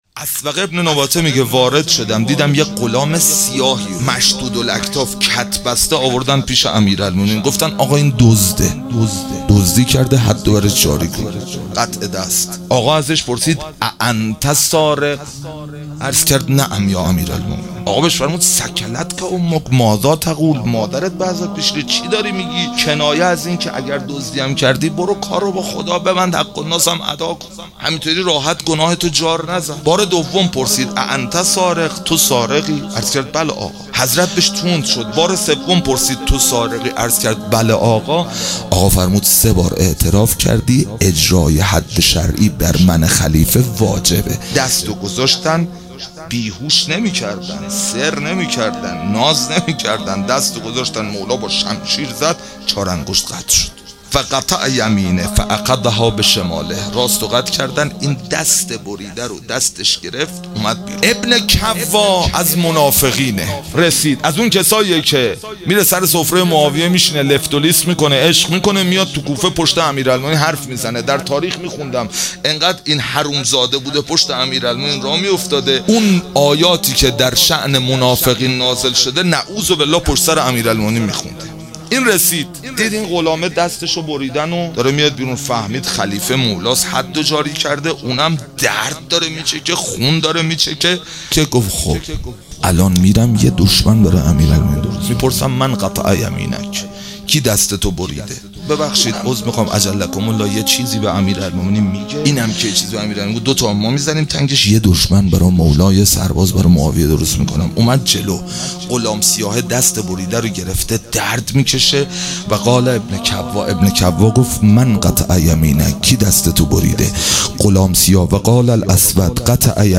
عید سعید غدیر خم | هیئت ام ابیها قم